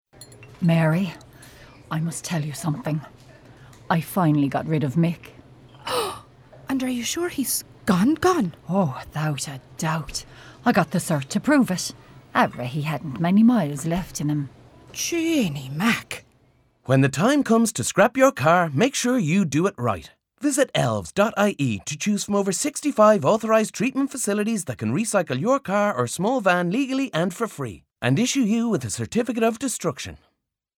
Elves-The-Right-Way-30-Sec-Radio.mp3